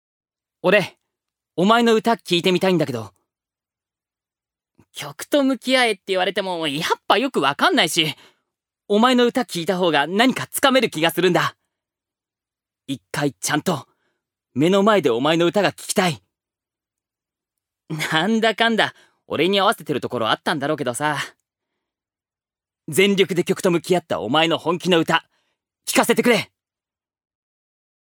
預かり：男性
セリフ１